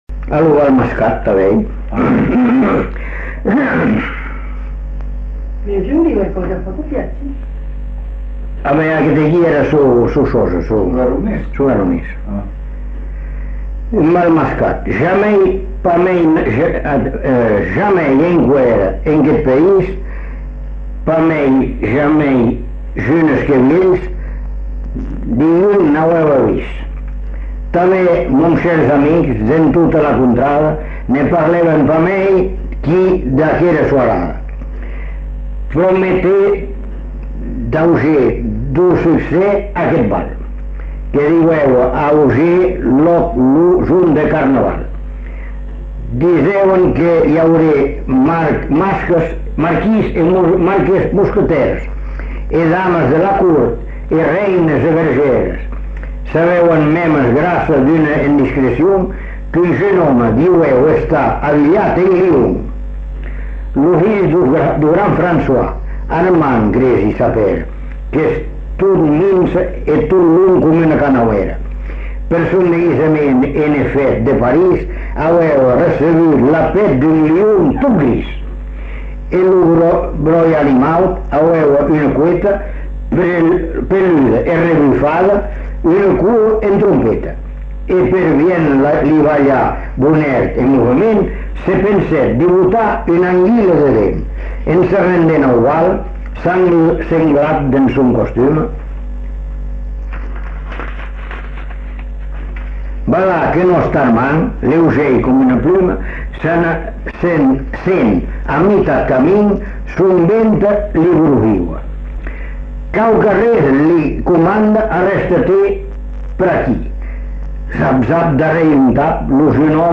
Aire culturelle : Bazadais
Lieu : Bazas
Genre : conte-légende-récit
Effectif : 1
Type de voix : voix d'homme
Production du son : lu